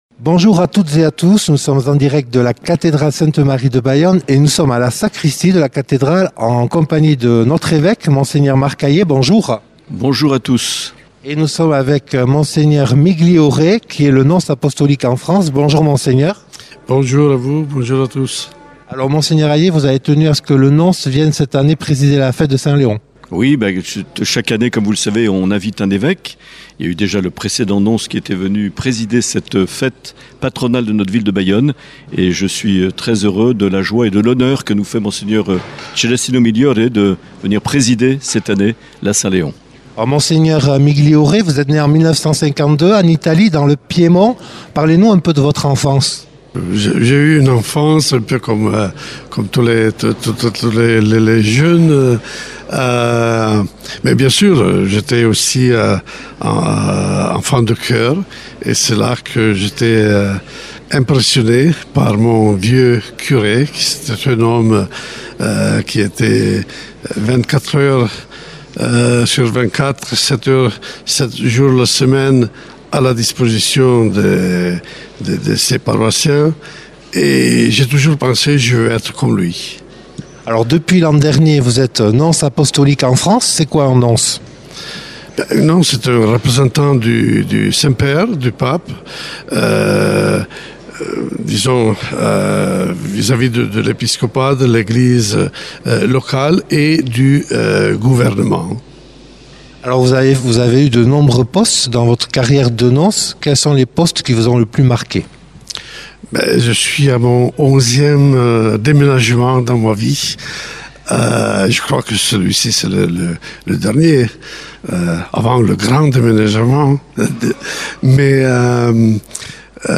Mgr Migliore a bien voulu répondre en direct à quelques unes de nos questions juste avant la messe de la solennité de saint Léon le 7 mars à la cathédrale de Bayonne.